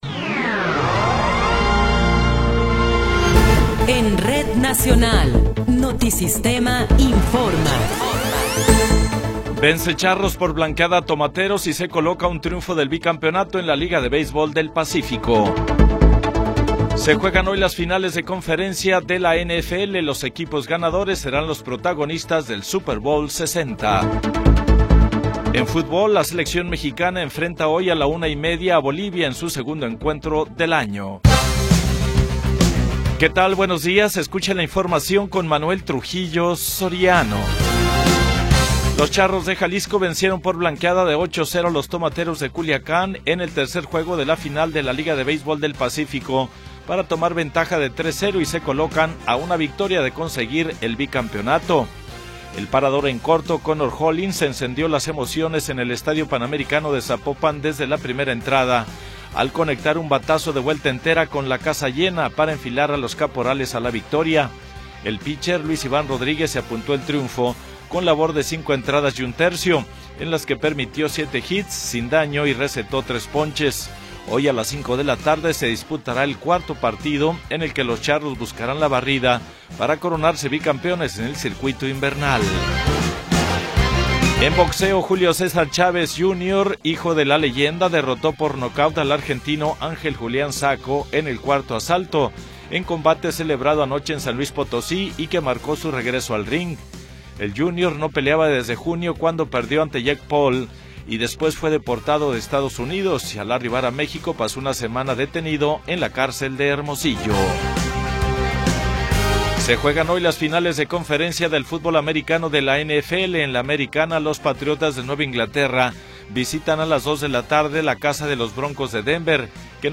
Noticiero 8 hrs. – 25 de Enero de 2026